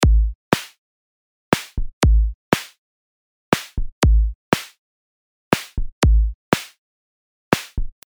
Sie werden also virtuell-analog erzeugt.
Oszillator Frequency steuert den Tonhöhenverlauf; die Wellenform ist Sinus.
Die Rechteckwelle hört sich ziemlich rau an: